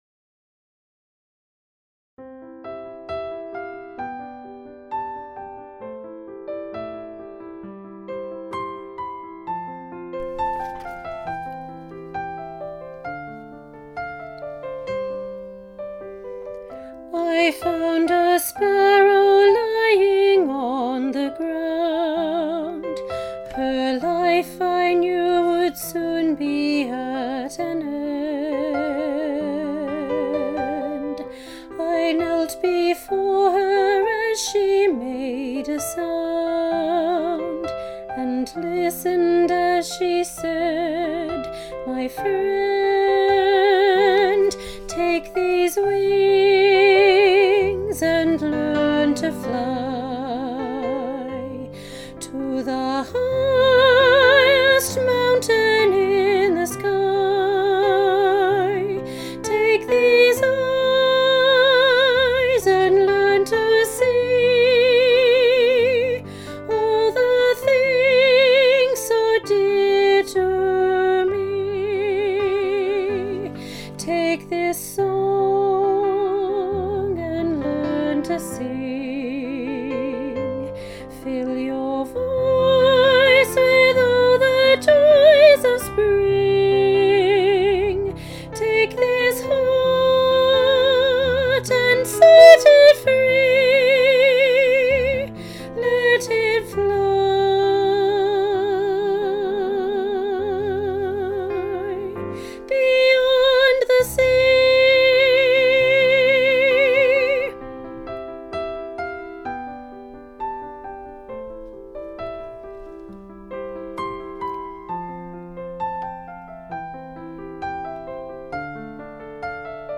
Junior-Choir-Take-these-wings-Part-1-top-part.mp3